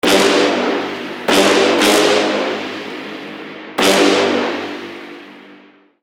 Это реальный семпл брасса только обработанный, возможно, что и Serum FX.
Возможно, лееринг из пары семплов..